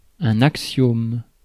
Ääntäminen
Ääntäminen US : IPA : /ˈæks.i.əm/